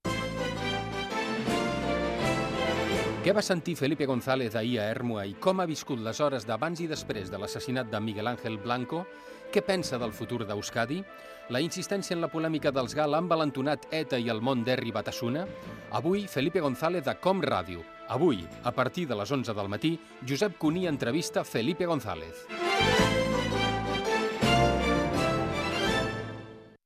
Promoció d'una entrevista al polític socialista Felipe González dins d'aquella edició del programa
Info-entreteniment